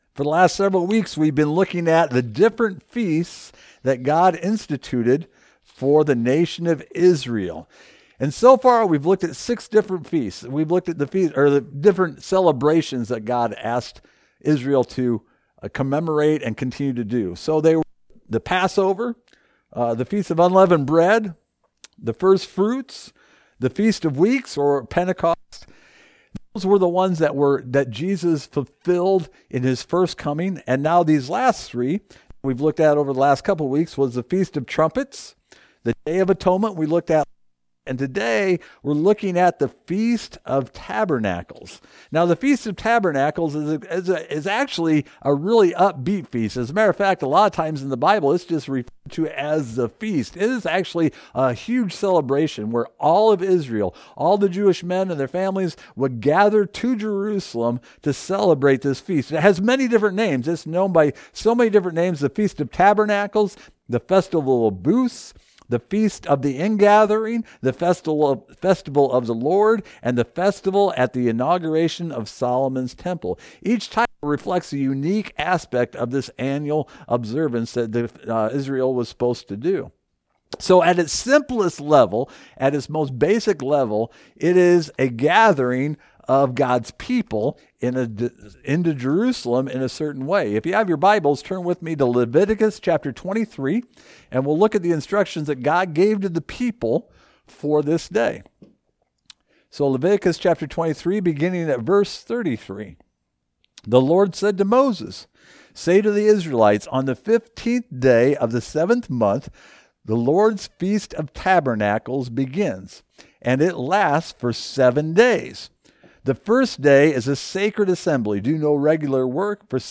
Service Type: Online Message